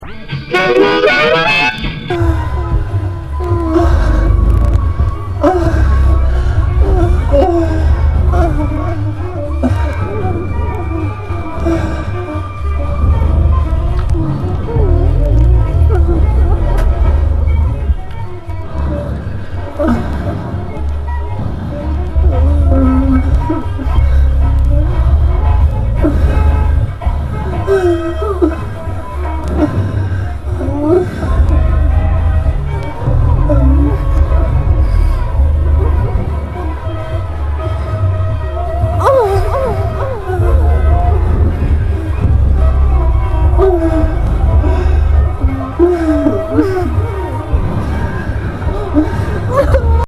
アダルト・ドローン＆ノイジー・アヴァンな珍品！